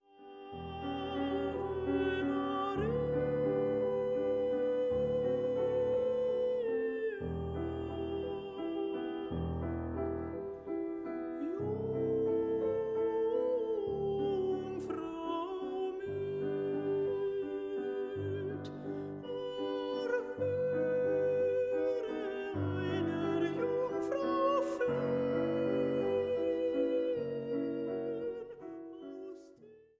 Contratenor
Piano